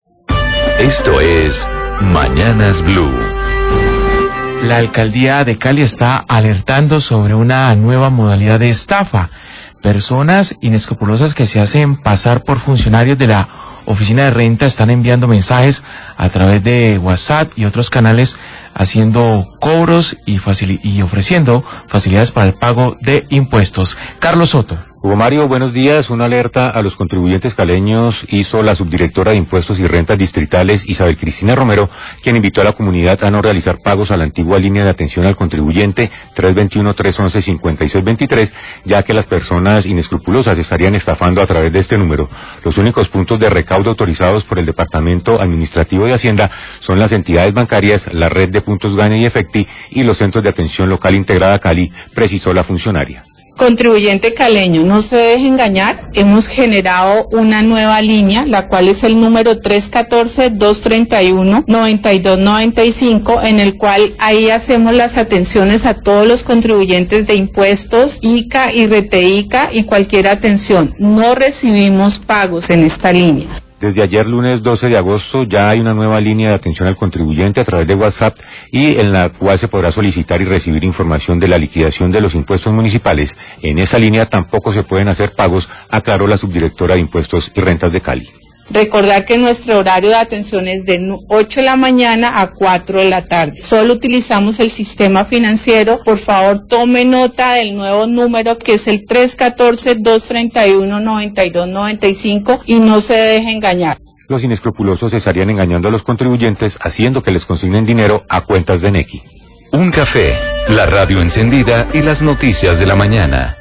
Radio
Desde la Alcaldía de Cali están alertando por una nueva modalidad de estafa donde inescrupulosos se hacen pasar por funcionarios de la oficina de rentas distrital, quienes ofrecen facilidades de pago de impuestos. Declaraciones de la subdirectora de impuestos y rentas distrital, Isabel Cristina Romero.